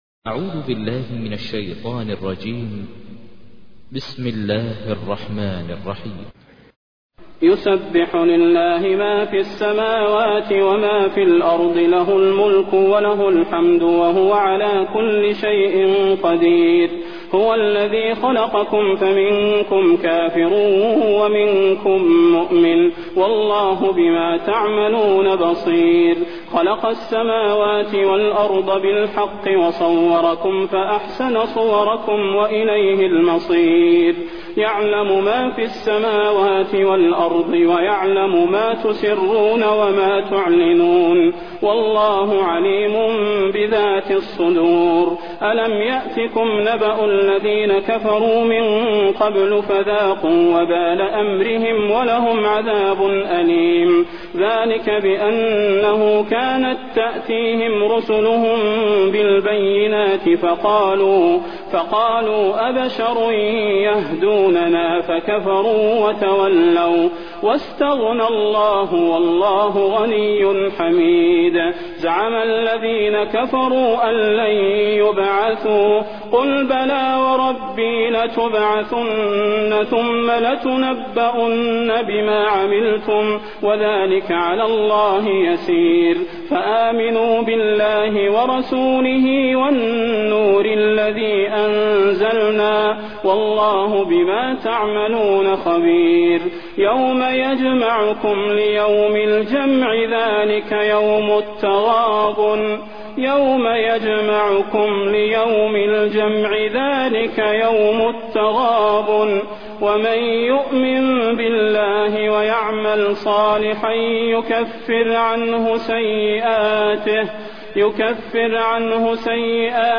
تحميل : 64. سورة التغابن / القارئ ماهر المعيقلي / القرآن الكريم / موقع يا حسين